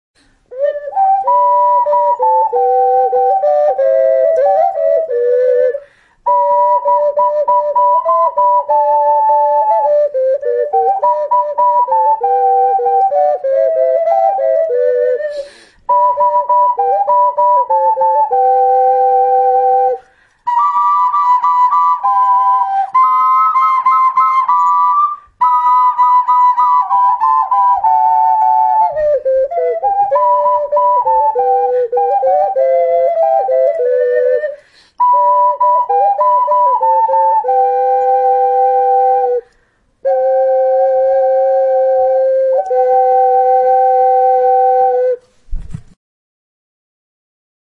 29) 御国への道（オカリナ演奏）